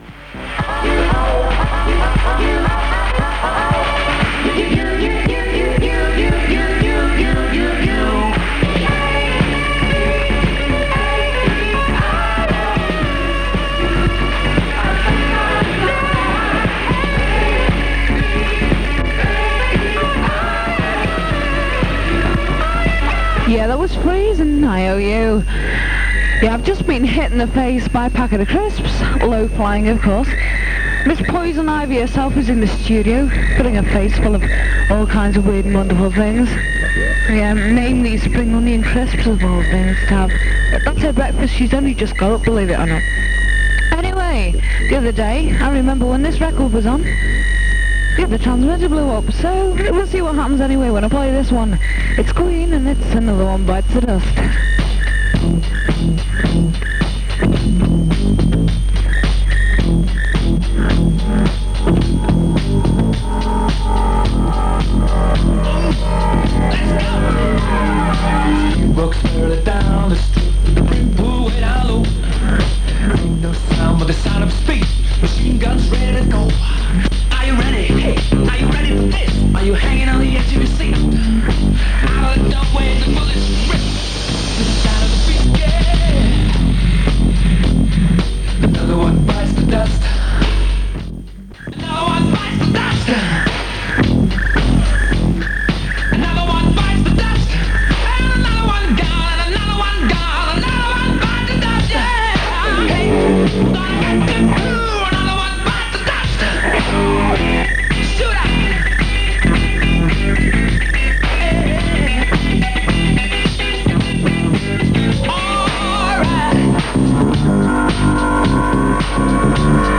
1983_11_05_sat_merseysound_1242am_test_tx-gh582.mp3 New Jan 2025 These are airchecks from a Merseysound test transmission as heard on Saturday 5th November 1983. At times there was some rf feedback on the microphone and a background signal causing wobbling. As received near Blackpool on 1242kHz between around 3pm and 4pm. 16MB 17mins